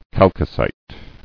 [chal·co·cite]